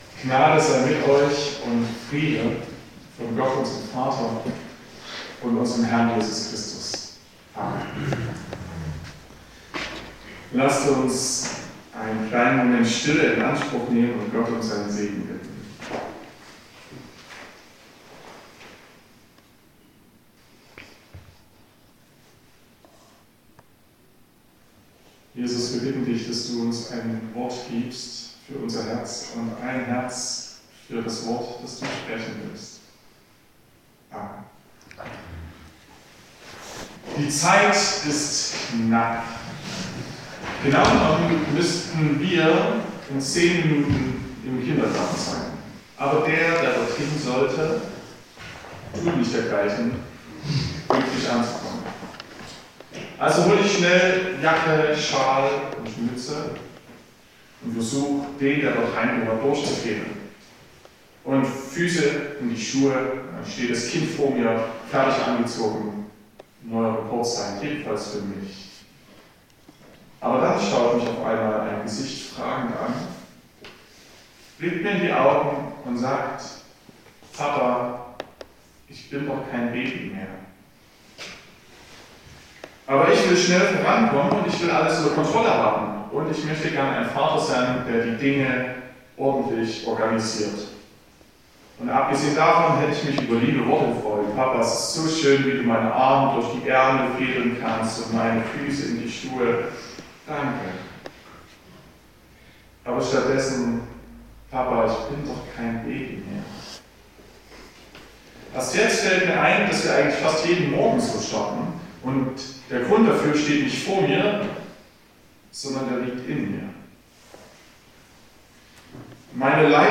08.02.2026 – Gottesdienst
Predigt und Aufzeichnungen